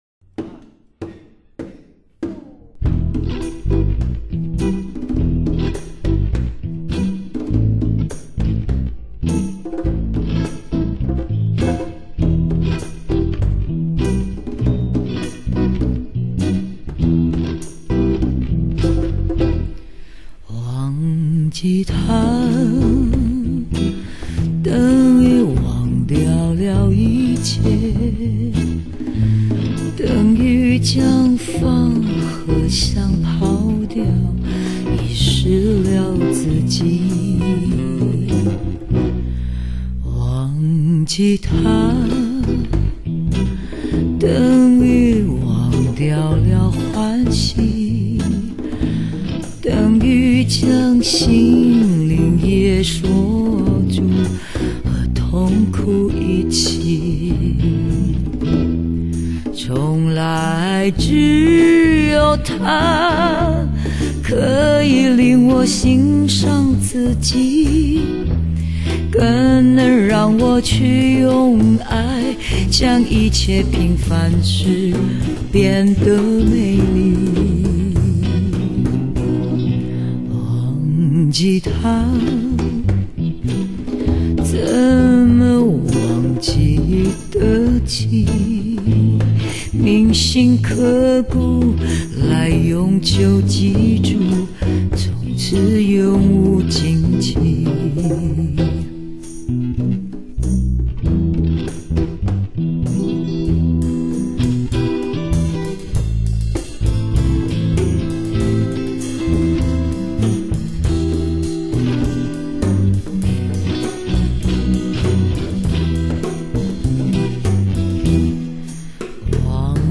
在7米高的錄音室裡one tack 一次完成， 絕無剪接
讓的超低音及難能可貴的喉底及氣聲、唇音齒聲展露無遺